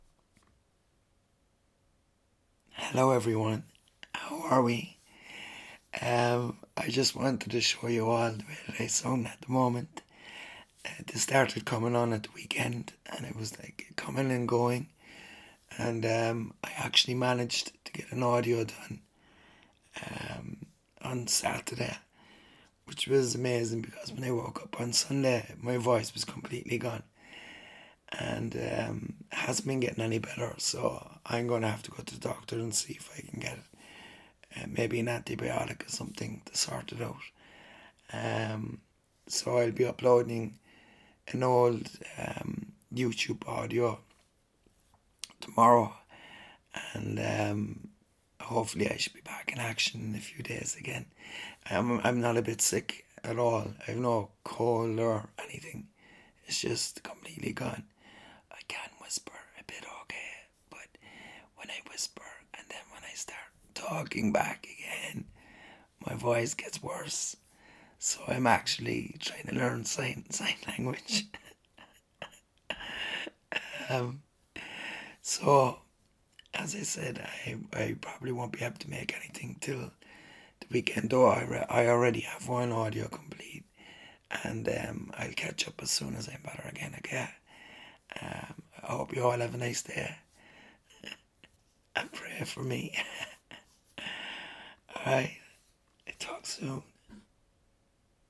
Hi all! This is the current state of my vocal chords!It came on during the weekend but I managed to get some PAs and an audio for the weekend.
Also, it may sound like I am sick but I'm actually as healthy as ever! Which is strange considering my voice!Give me a few days and I'll be back.....but in the meantime there are one or two audios coming ;)